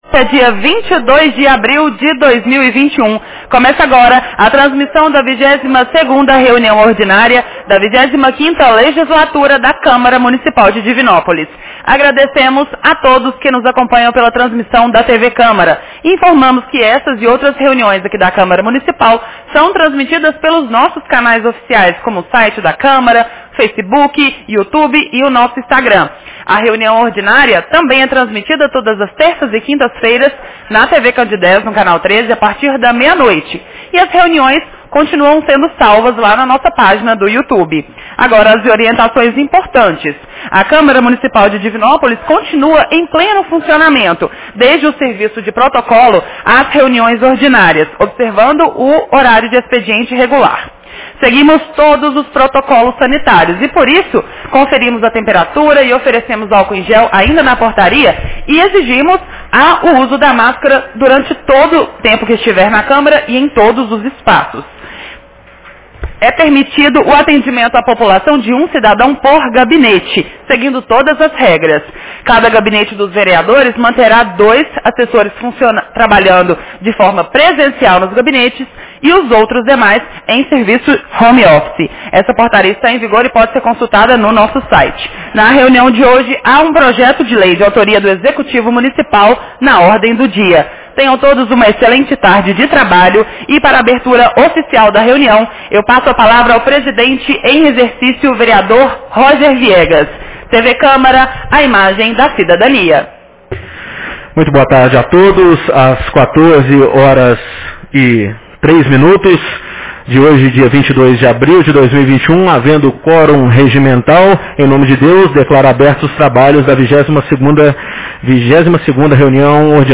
Reunião Ordinária 22 de 22 de abril 2021